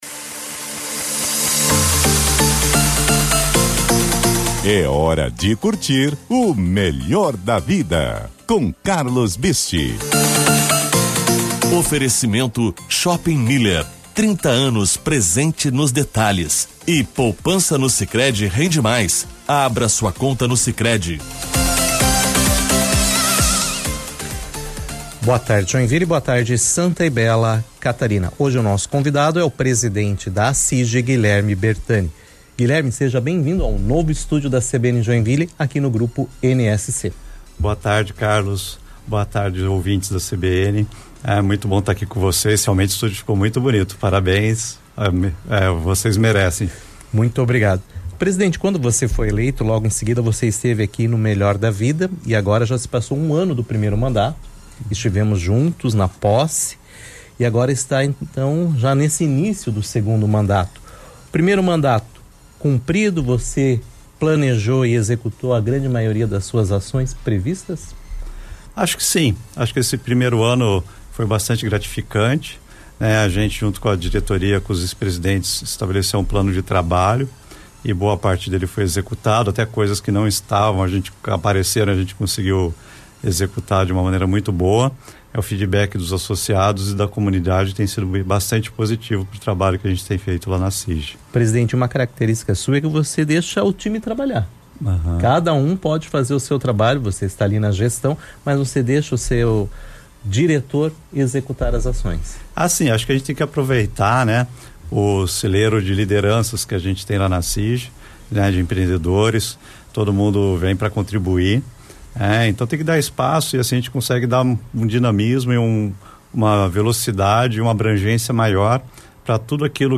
A entrevista foi veiculada pela CBN na última segunda-feira, dia 29 de setembro.
entrevista-presidente.mp3